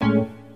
NEPTUNESpiano01.wav